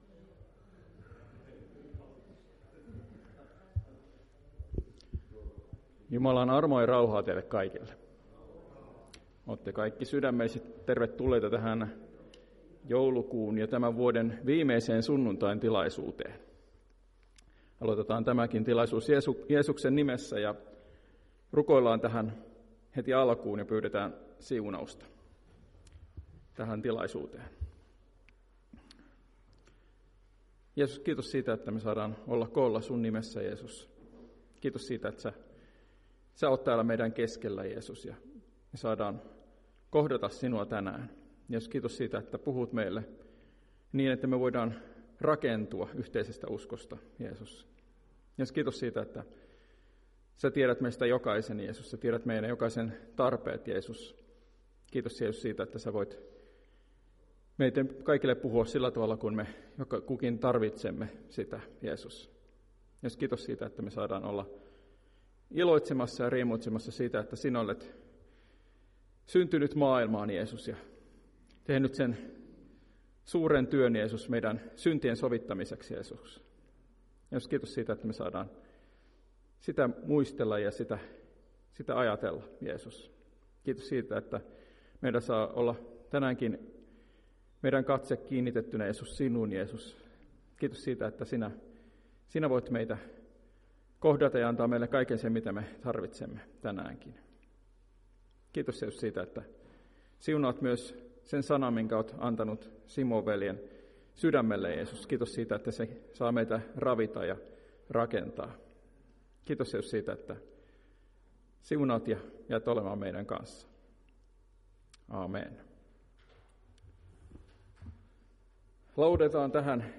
Päiväkokous 29.12.2024